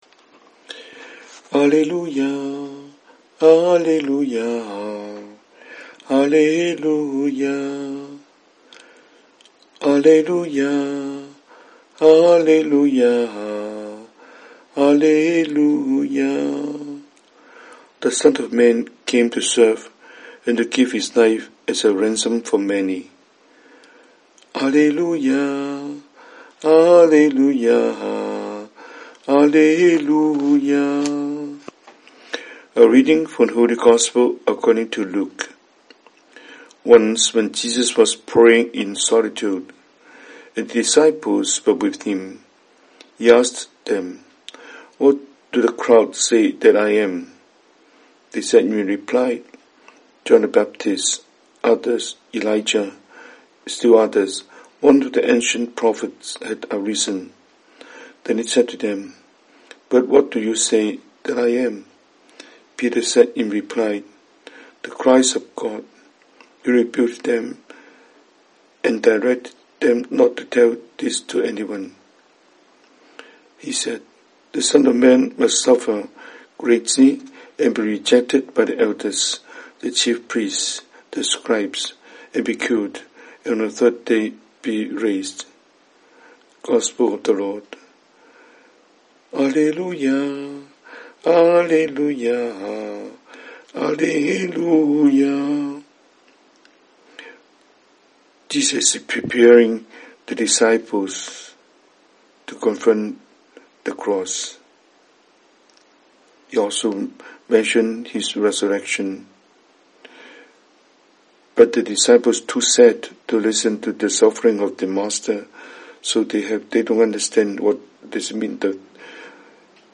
神父講道